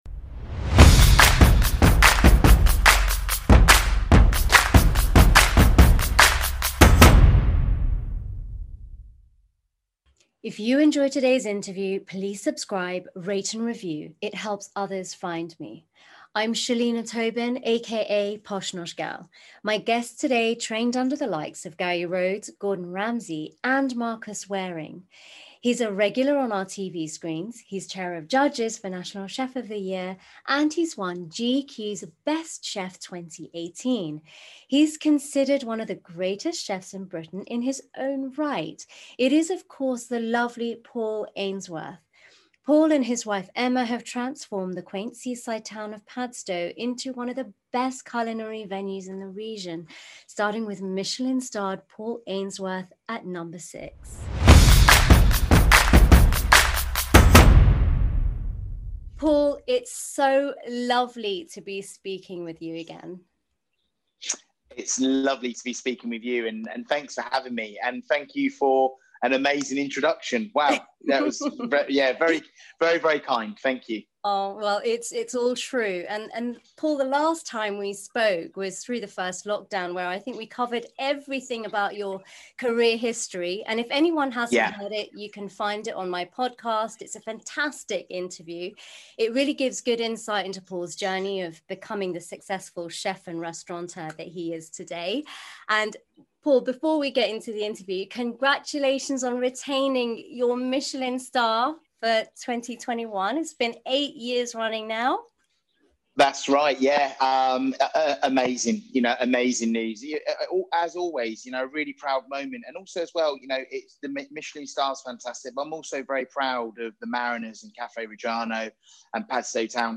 Paul's incredibly deep, touching and powerful story of the loss of a loved one and his coping mechanism for grief. Please be advised this interview is descriptive in detail of what one may experience when dealing with pancreatic cancer.